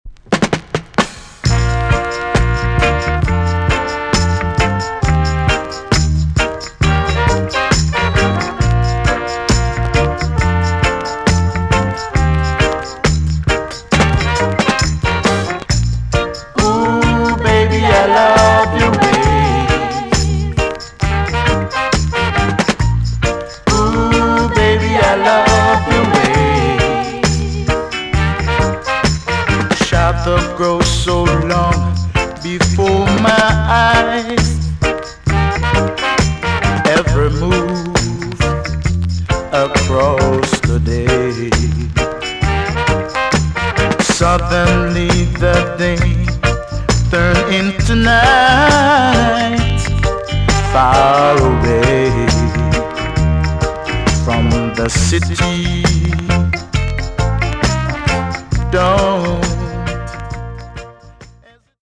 TOP >80'S 90'S DANCEHALL
EX-~VG+ 少し軽いチリノイズがありますが良好です。